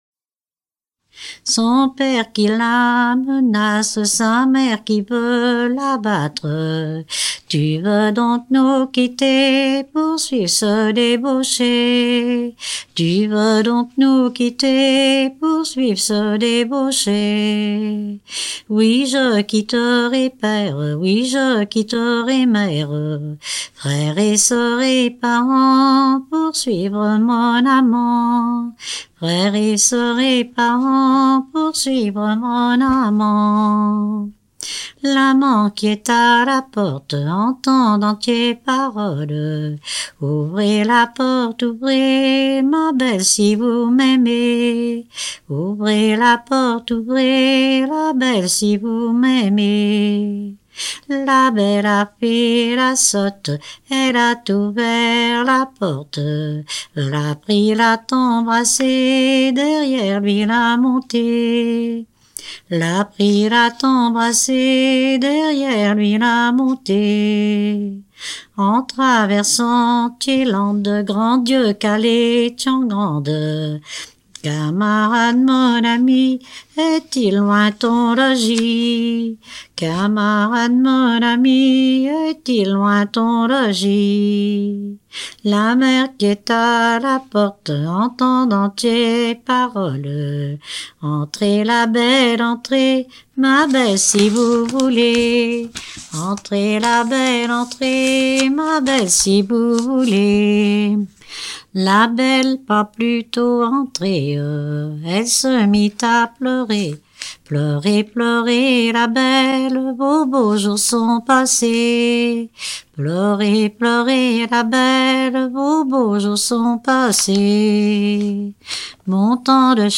Dompierre-sur-Yon
Genre strophique
Pièce musicale éditée